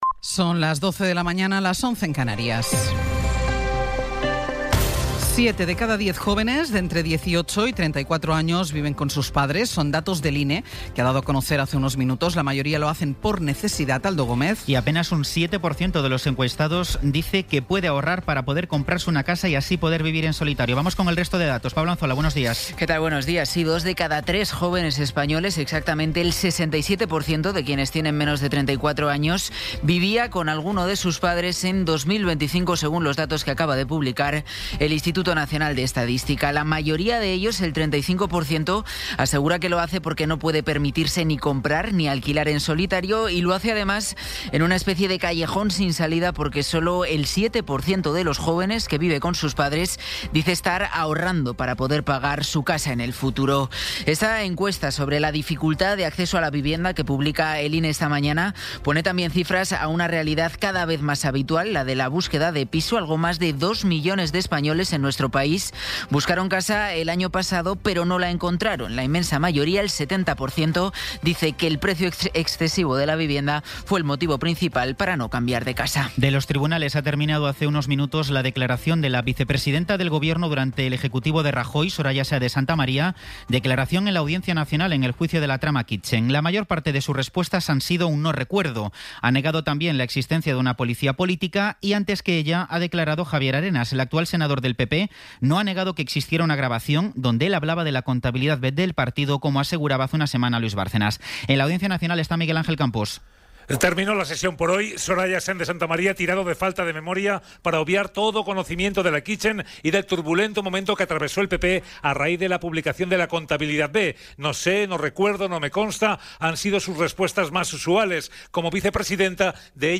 Resumen informativo con las noticias más destacadas del 27 de abril de 2026 a las doce.